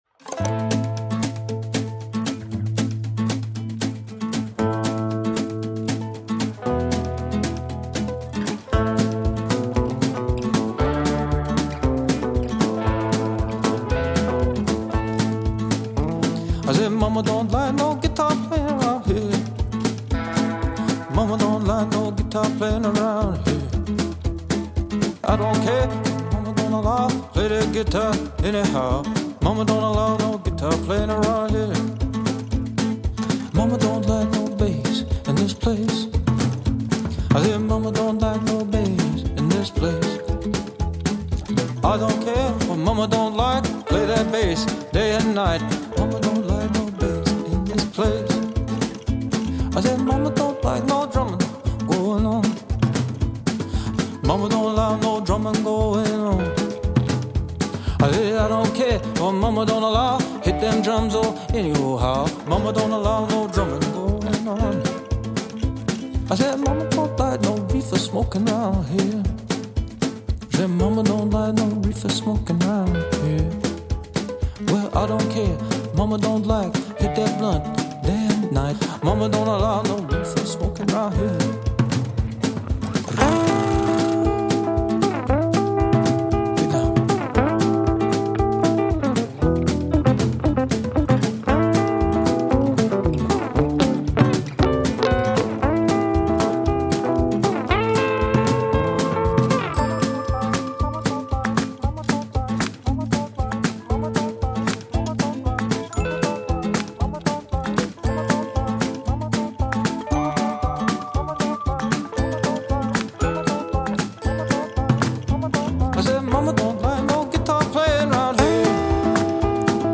музыка в стиле  Джи Джи Кейла